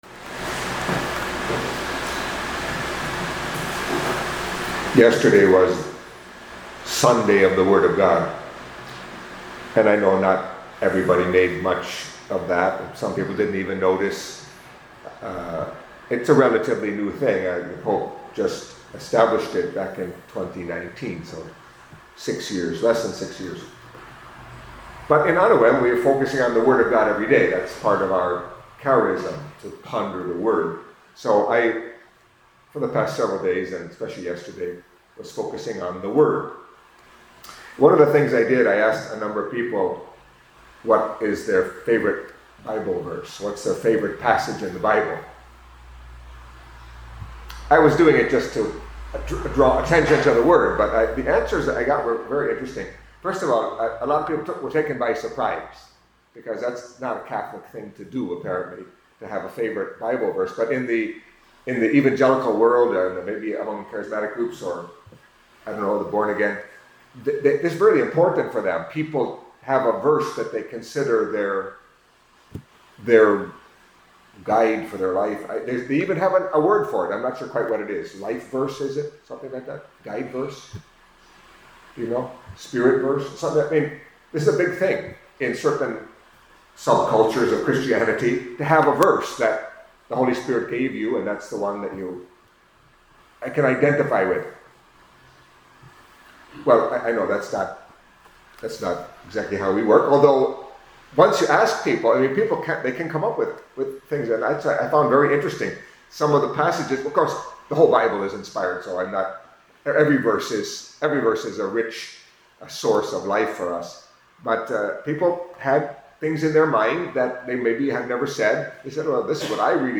Catholic Mass homily for Monday of the Third Week in Ordinary Time